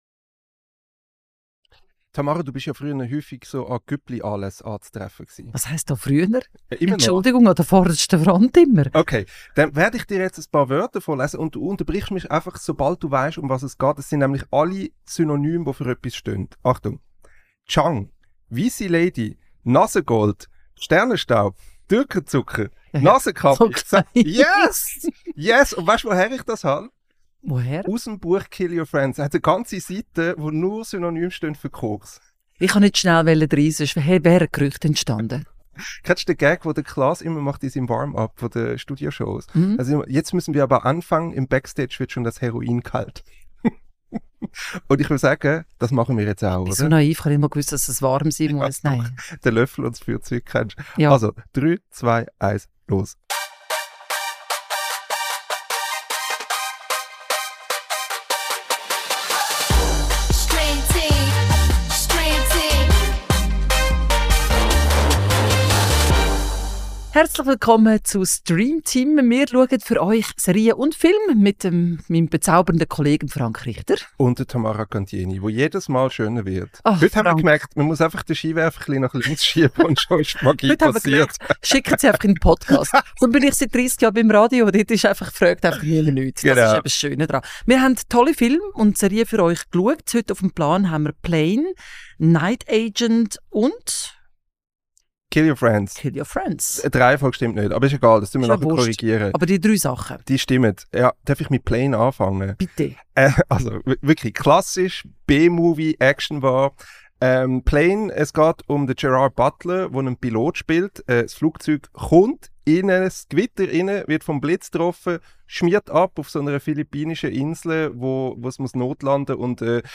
Episode 9 | Plane, Kill Your Friends, The Night Agent ~ Streamteam | Der erste Film- und Serienpodcast auf Schweizerdeutsch Podcast